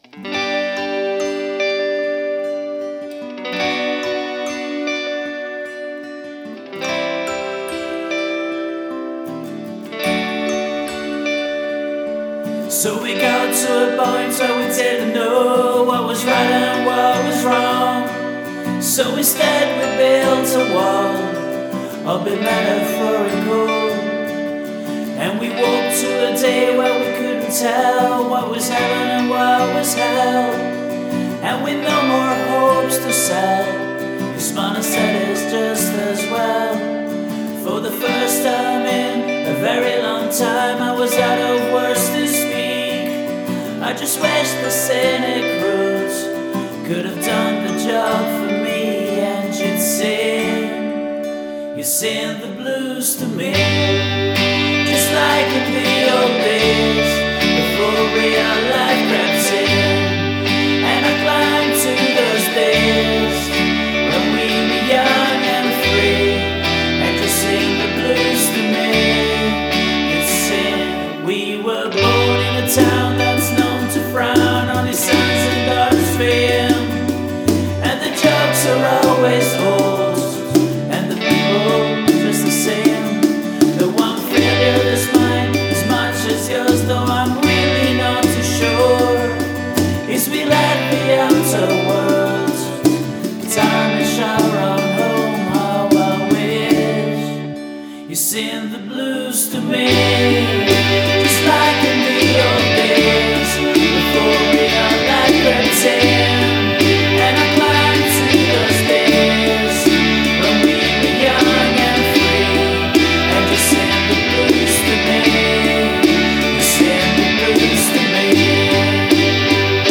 vocals, guitars, bass, drums, keyboards